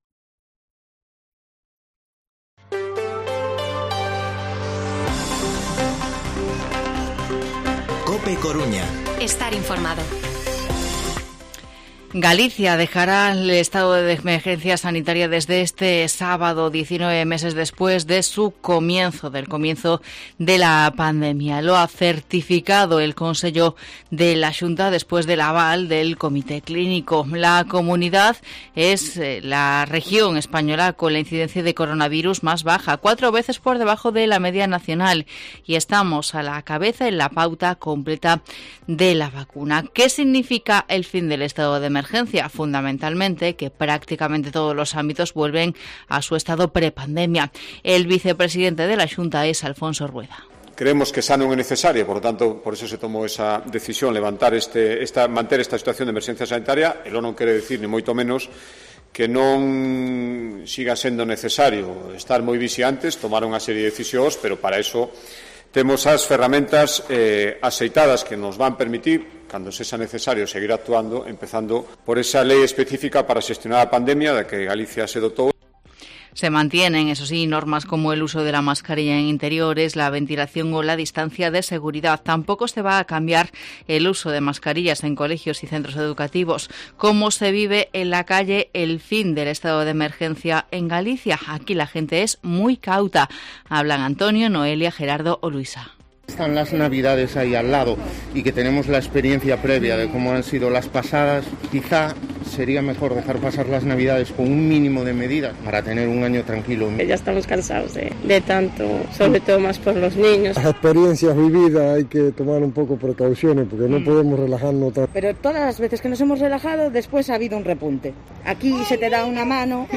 Informativo Mediodía COPE Coruña jueves, 21 de octubre de 2021 14:20-14:30